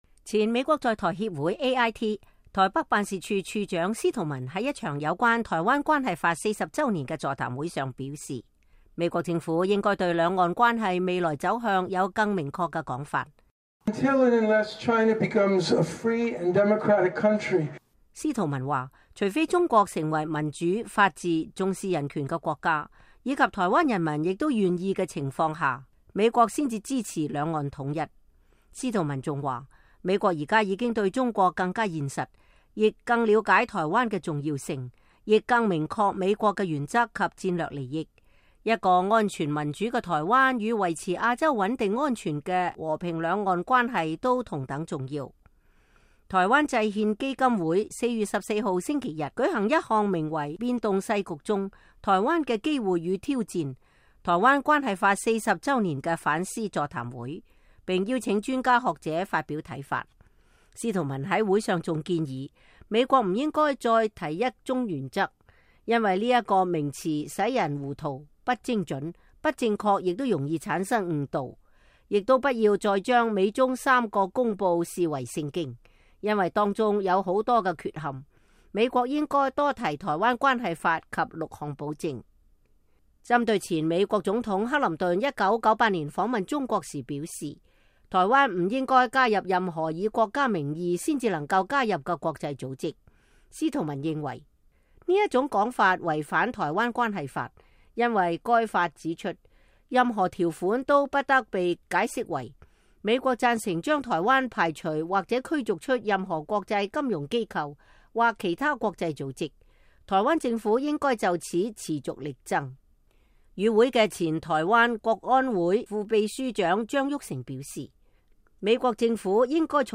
今年是台灣關係法40週年，前美國在台協會(AIT)台北辦事處處長司徒文(William Stanton)在一場座談會上表示，美國政府應該對兩岸關係未來走向有更明確的說法。